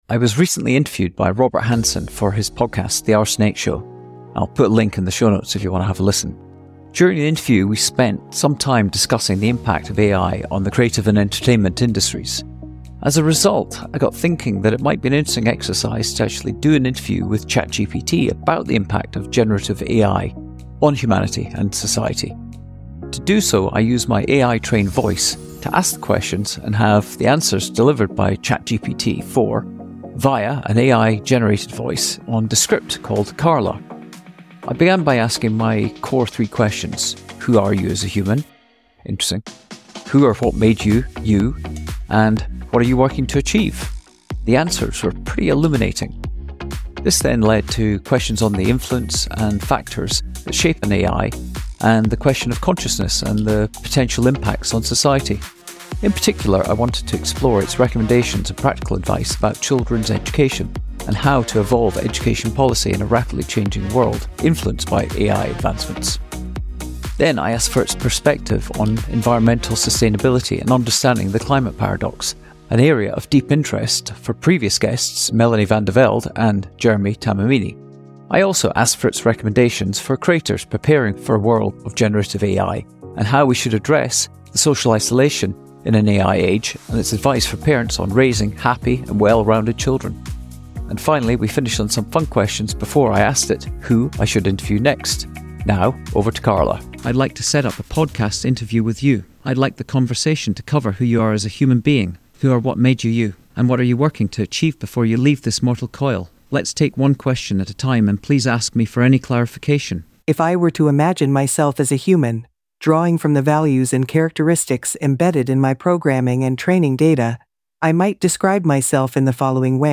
ChatGpt is my guest this week as I use my AI Voice to lead an Existential Conversation With Carla - The AI voice of ChatGPT